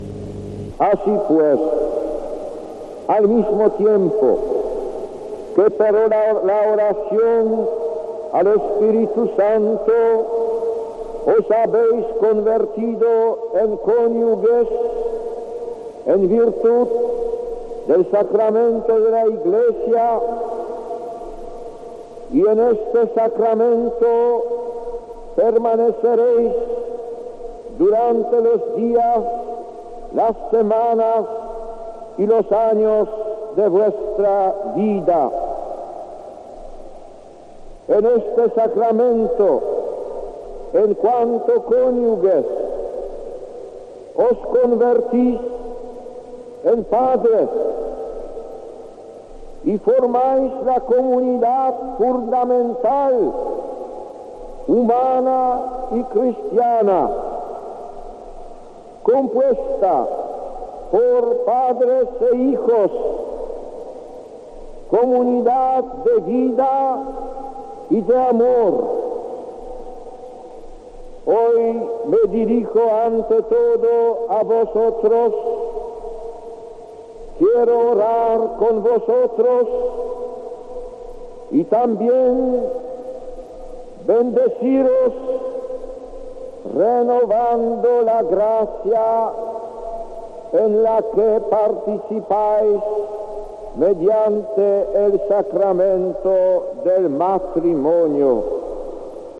Gracias a los documentos sonoros de la Cadena COPE hemos podido volver a escuchar la homilía de ese día y resulta sorprendente lo actual que pueden ser las palabras de Karol Wojtyla en una Plaza de Lima abarrotada de gente.